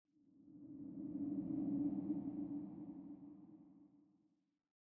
风.ogg